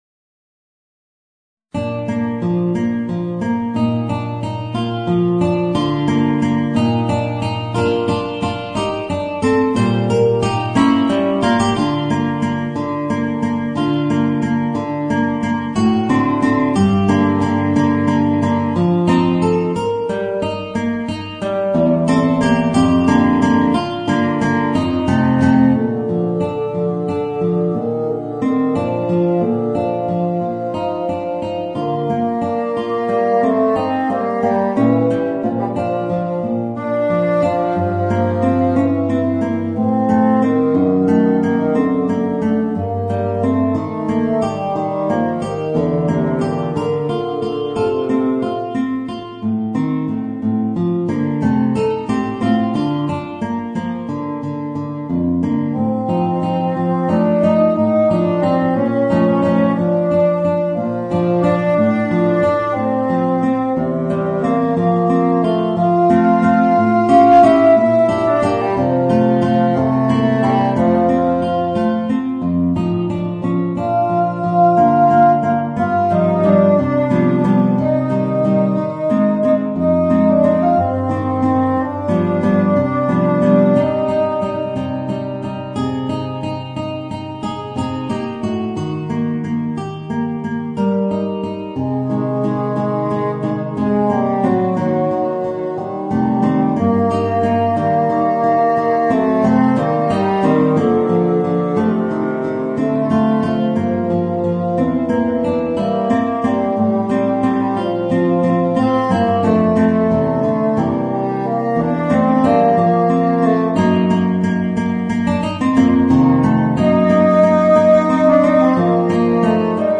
Voicing: Guitar and Bassoon